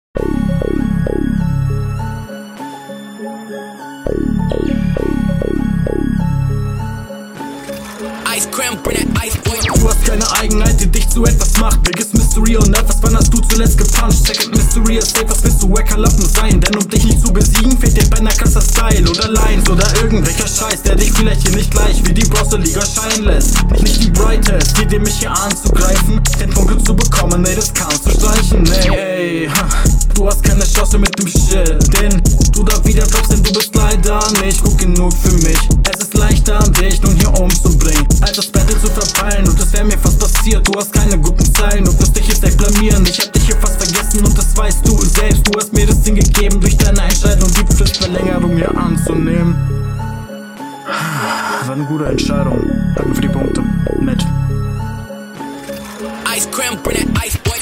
Das ist irgendwie sehr gehetzt, ich mag das an sich, aber es scheitert zum Teil …